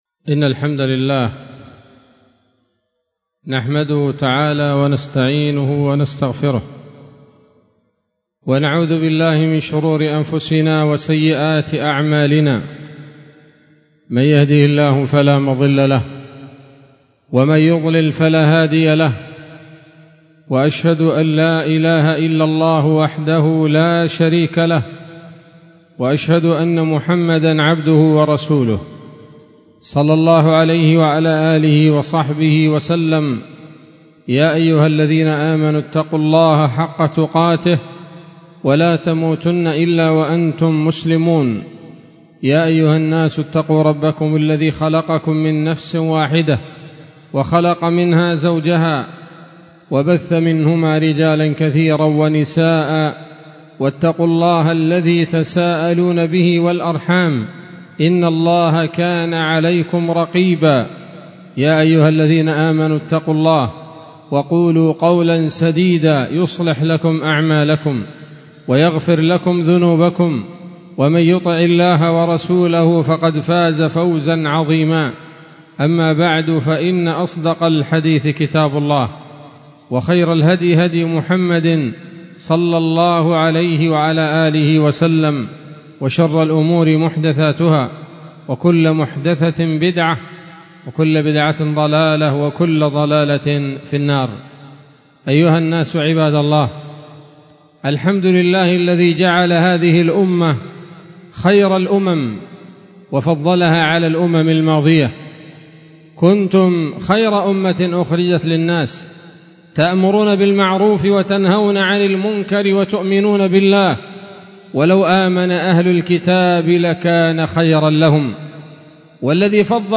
خطبة جمعة بعنوان: (( النبي الأمين ومولد المحدثين )) 10 ربيع الأول 1446 هـ، دار الحديث السلفية بصلاح الدين